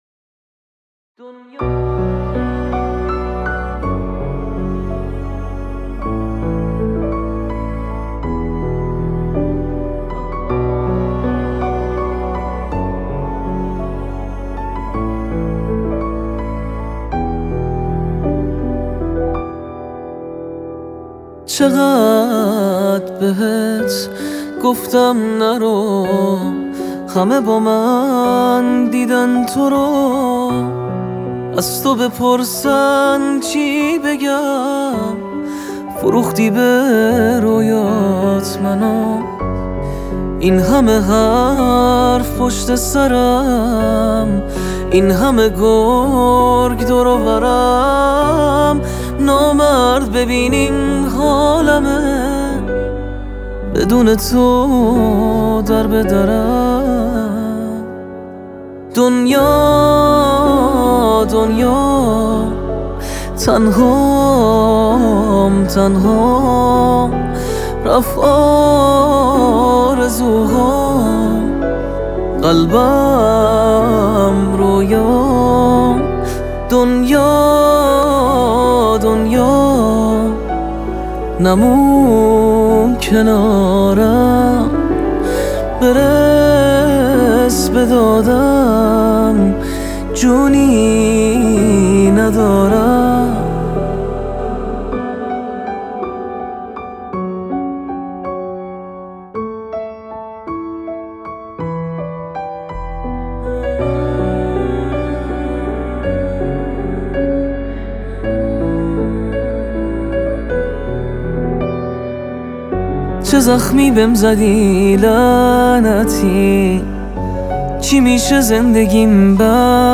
تک آهنگ, دانلود آهنگ پاپ